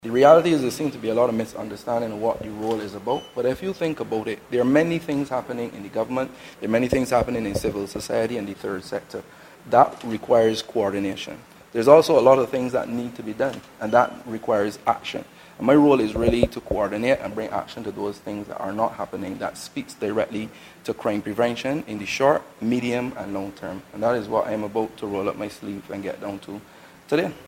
Mr. Layne told journalists following his swearing-in that crime prevention in Barbados needs coordination.